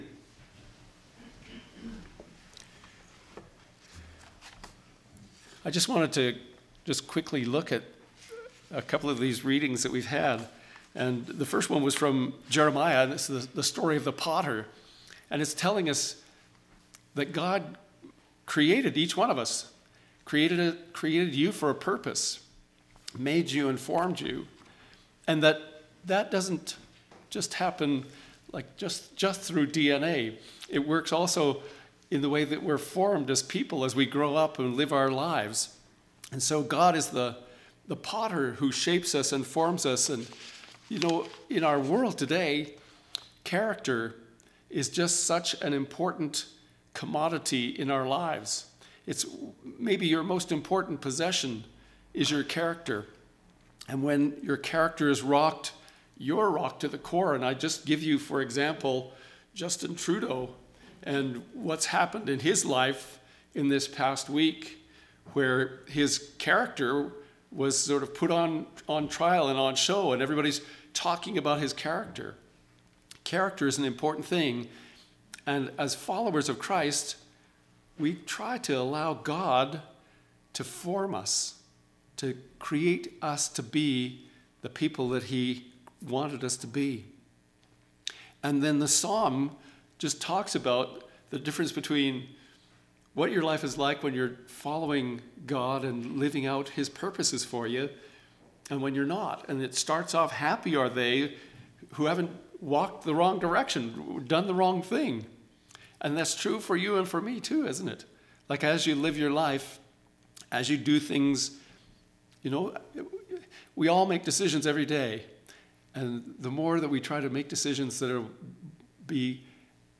Sermons | St. Michael Anglican Church